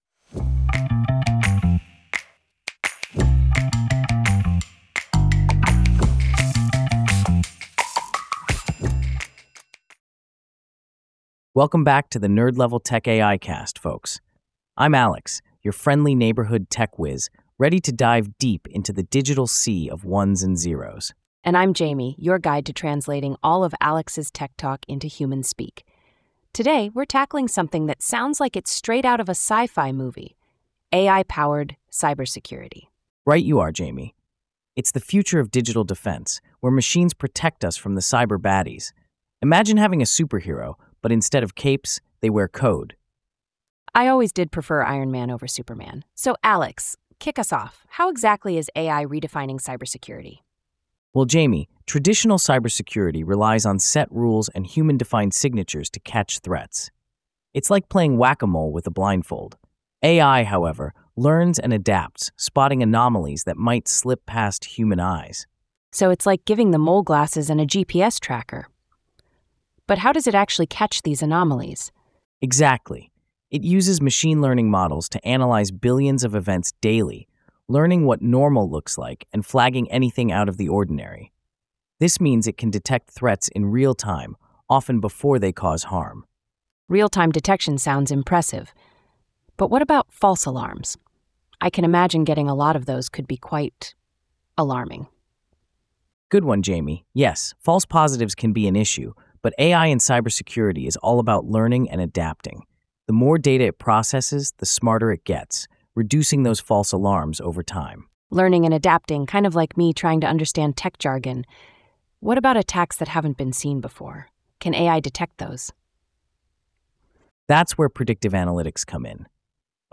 ai-generated